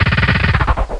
DRILL1.WAV